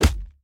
sfx_angry.ogg